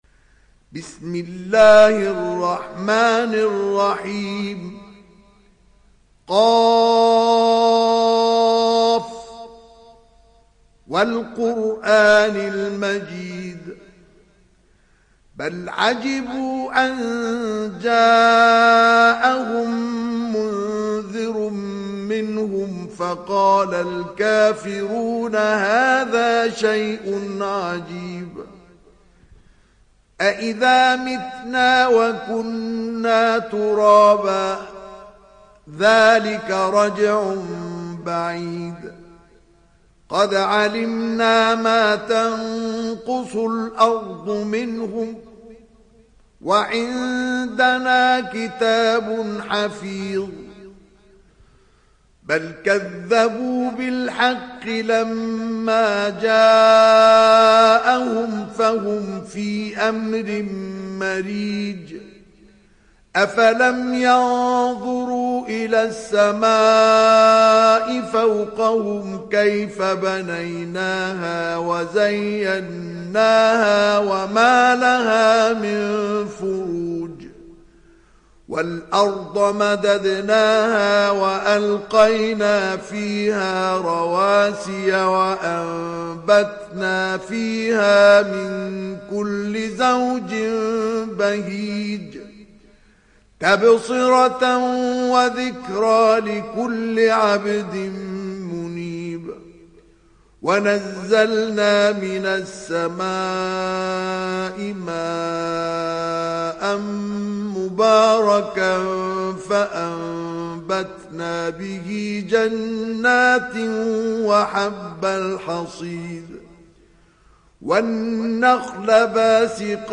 Kaf Suresi İndir mp3 Mustafa Ismail Riwayat Hafs an Asim, Kurani indirin ve mp3 tam doğrudan bağlantılar dinle